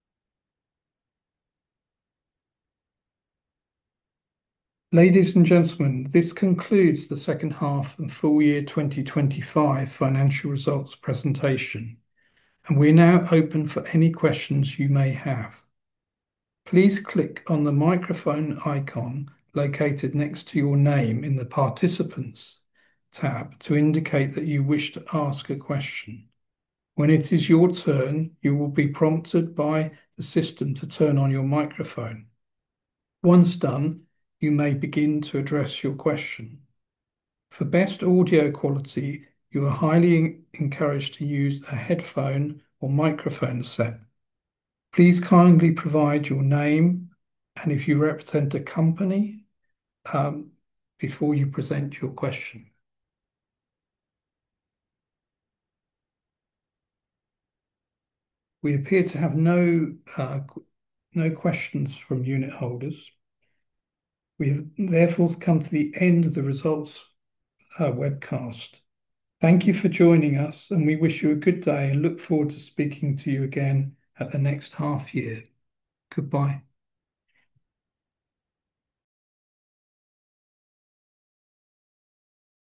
Audio Webcast - Q&A (888 KB)
2H_FY2025_Audio_Webcast-Q&A.mp3